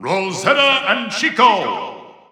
The announcer saying Rosalina and Luma's names in Japanese and Chinese releases of Super Smash Bros. 4 and Super Smash Bros. Ultimate.
Rosalina_&_Luma_Japanese_Announcer_SSB4-SSBU.wav